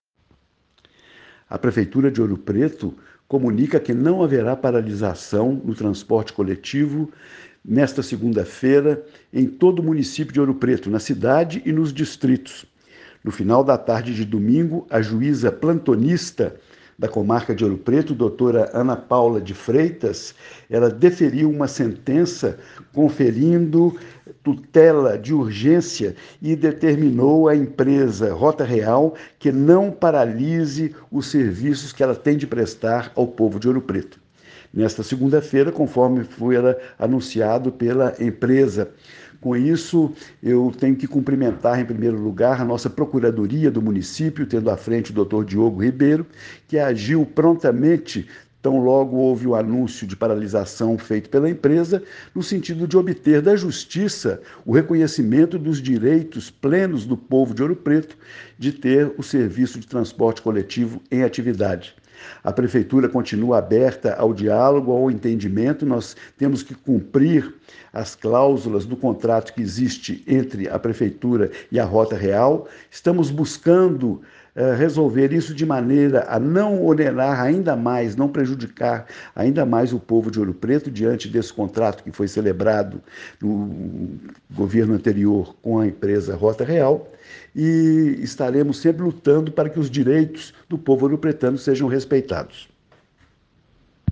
A seguir a fala do Prefeito Angelo Oswaldo sobre a liminar conquistada pela Prefeitura: